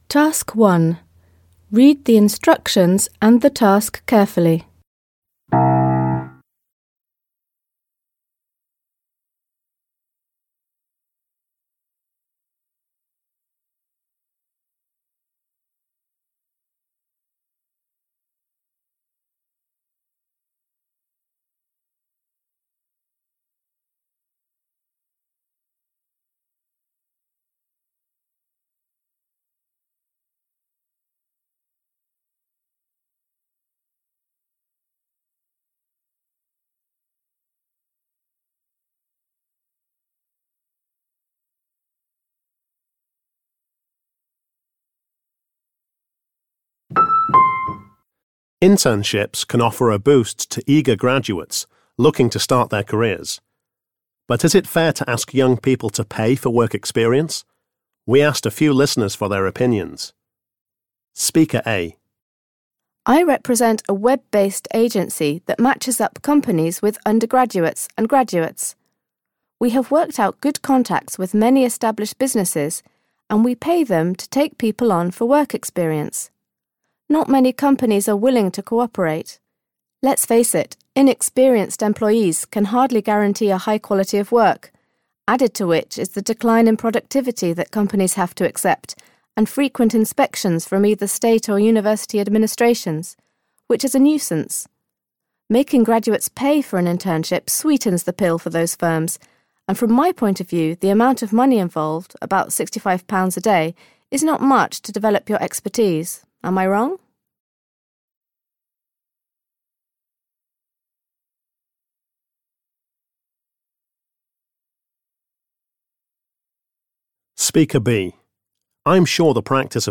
You are going to hear three people talking about internships.